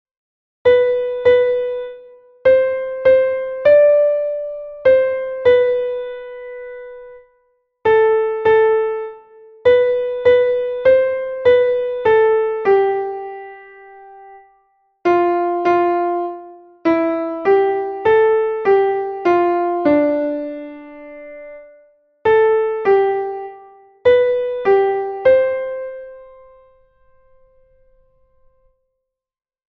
Here there are four 6/8 time signature exercises.